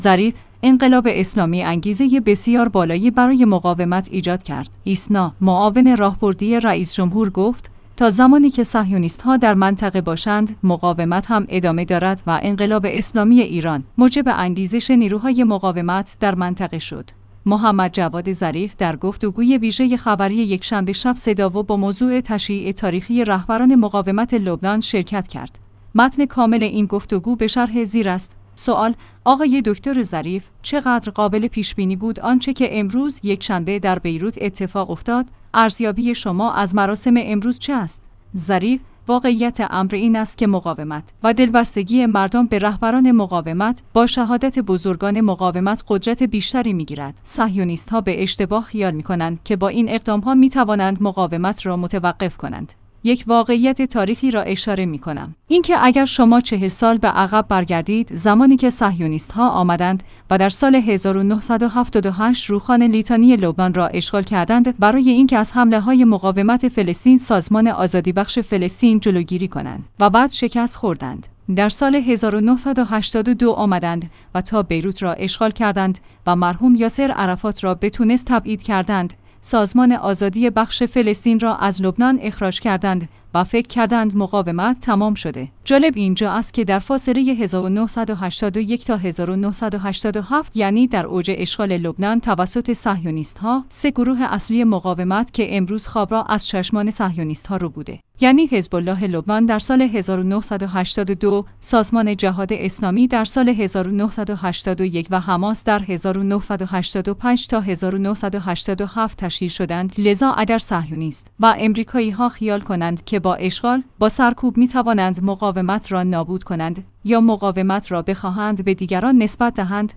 محمدجواد ظریف در گفتگوی ویژه خبری یکشنبه شب صدا و سیما با موضوع تشییع تاریخی رهبران مقاومت لبنان شرکت کرد.